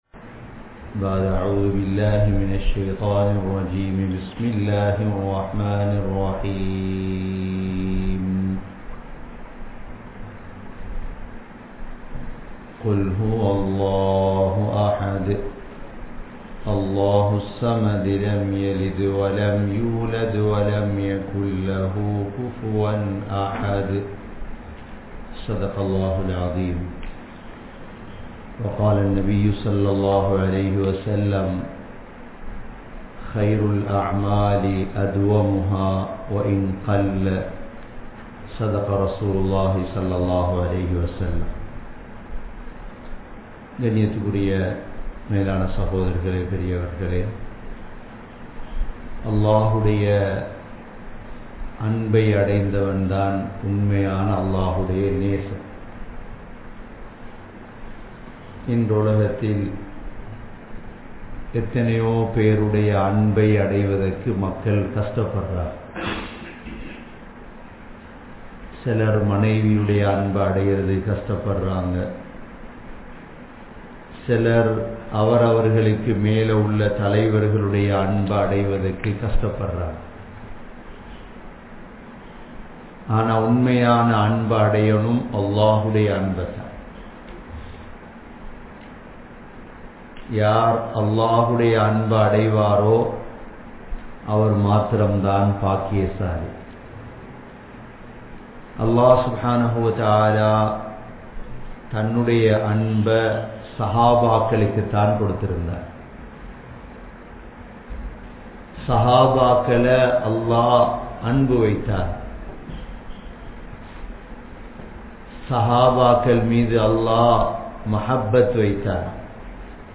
Audio Bayans
Canada, Toronto, Thaqwa Masjidh